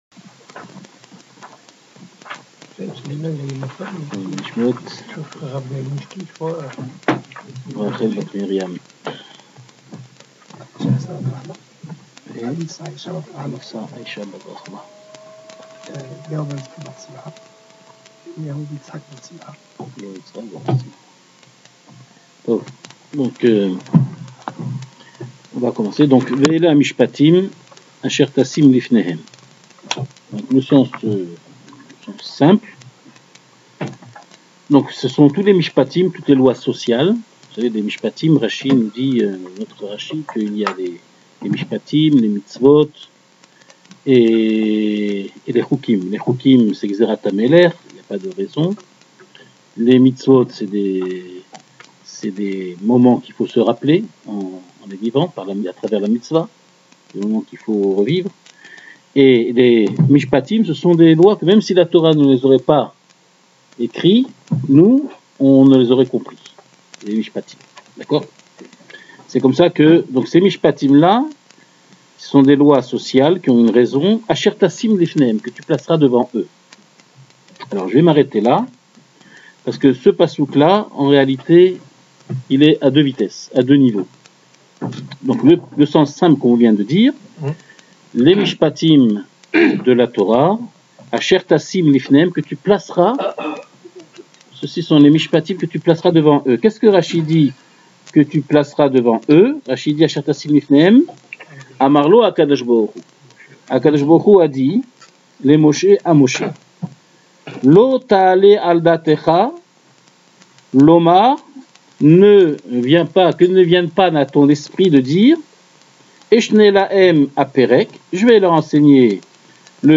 Le cours est donné tous les jeudis soir.